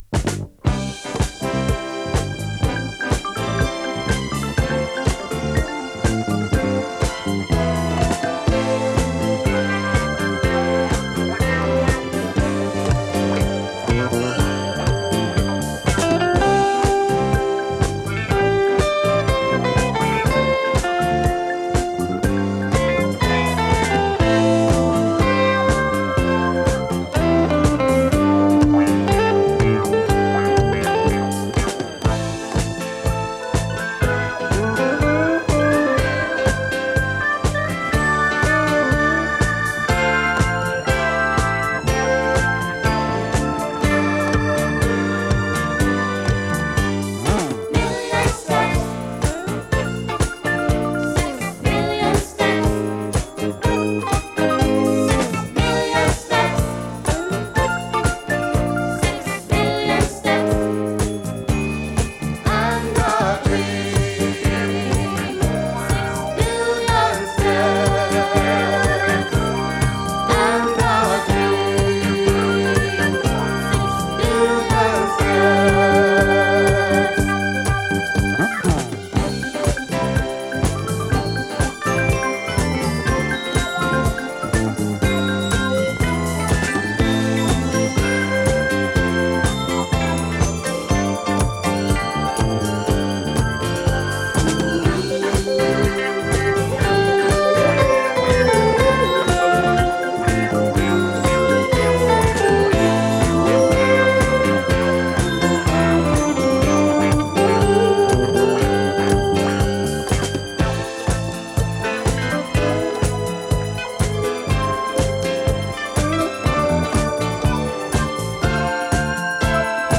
流麗 スペイシー フィリー系 インスト ディスコ
♪Full Length Vocal Version 5.45)♪